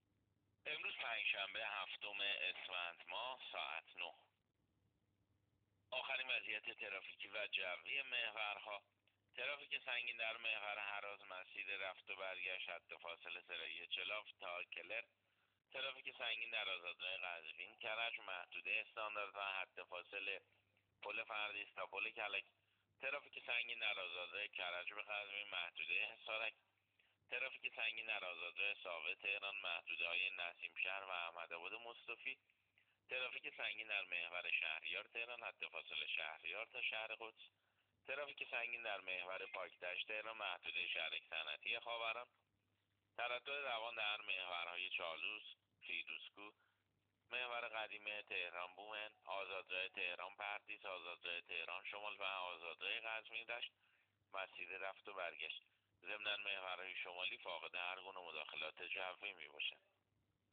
گزارش رادیو اینترنتی از آخرین وضعیت ترافیکی جاده‌ها ساعت ۹ هفتم اسفند؛